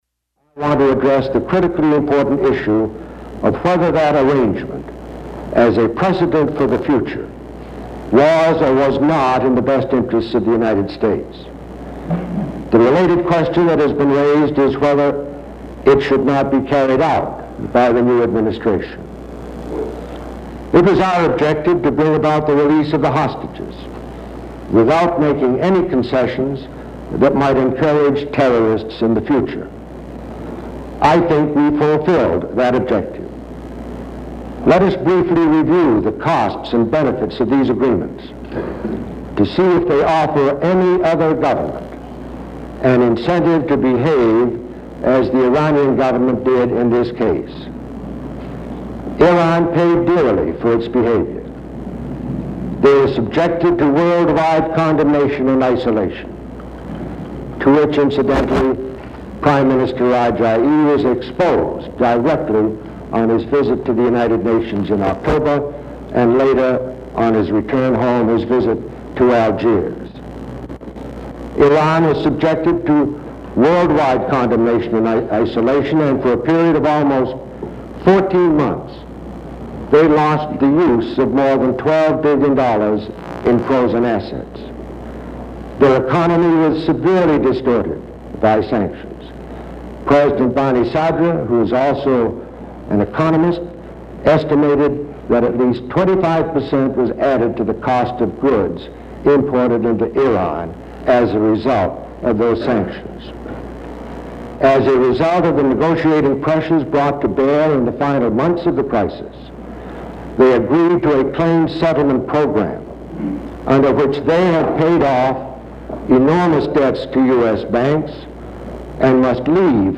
Edmund Muskie tells a Congressional committee that the United States came out of the Iran hostage crisis victorious on all levels
Broadcast on PBS, February 17, 1981.